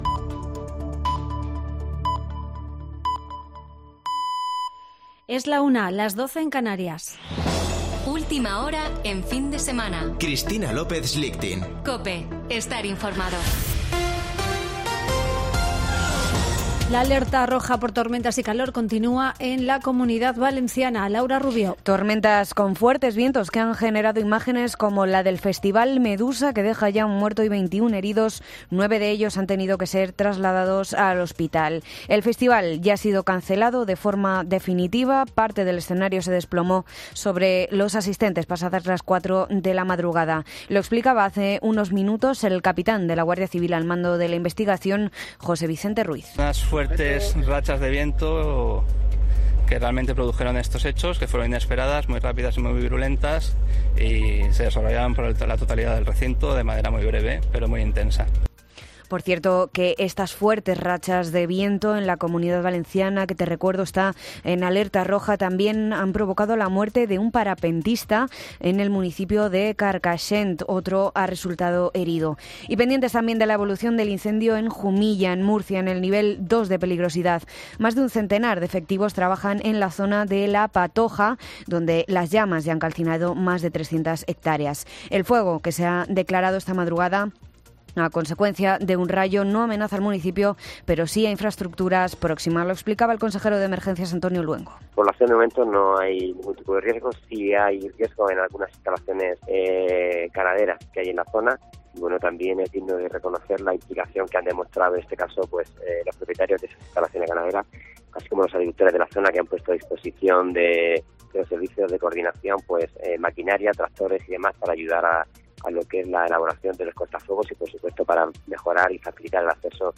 Boletín de noticias de COPE del 13 de agosto de 2022 a la 13.00 horas